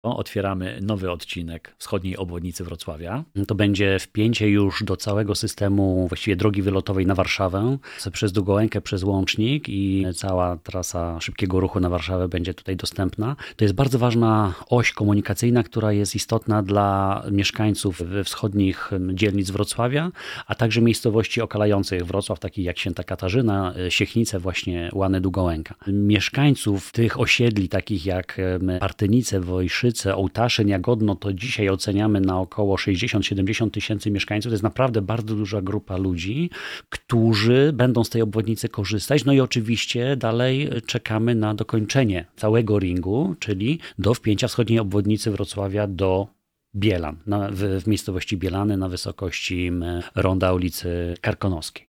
O inwestycji rozmawiamy z Michałem Bobowcem – radnym Sejmiku Województwa Dolnośląskiego.
We wtorek 20 grudnia zostanie otwarty 10-kilometrowy, odcinek między Łanami a Długołęką, mówi Michał Bobowiec – radny sejmiku województwa dolnośląskiego.